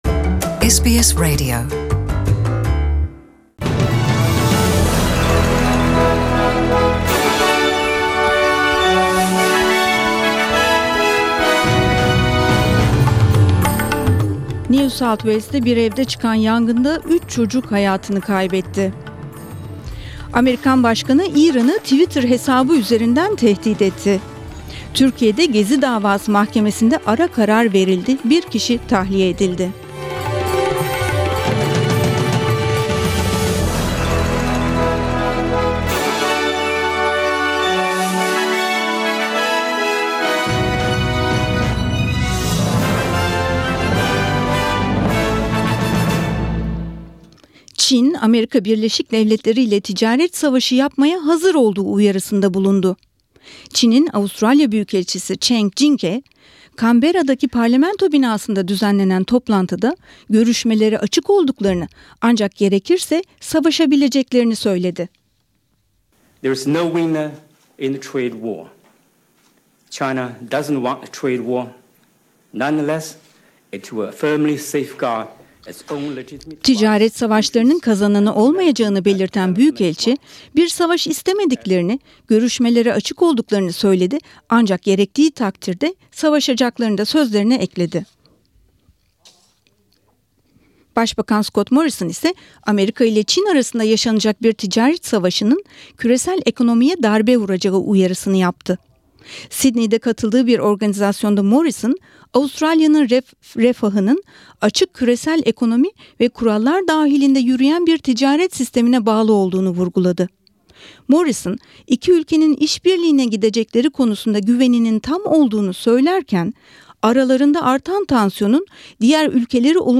SBS Türkçe Haberler